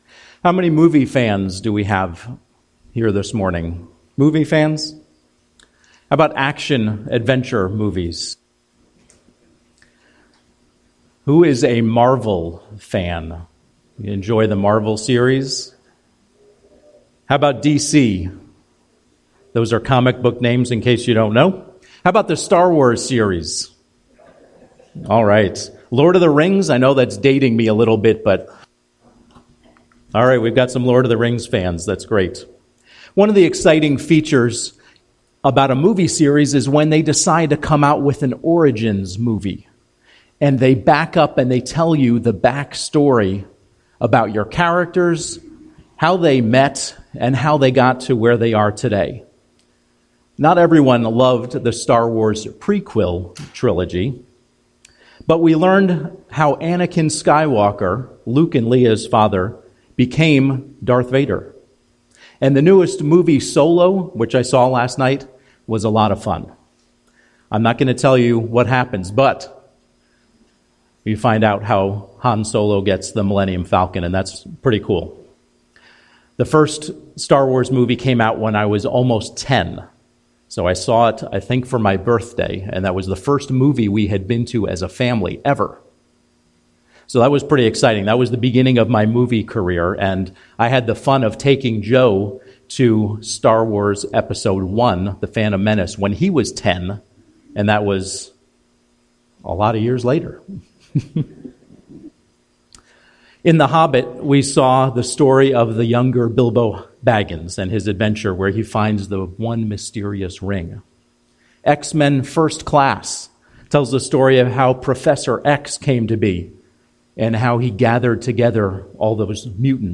Home › Sermons › Genesis – Origins